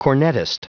Prononciation du mot : cornettist
cornettist.wav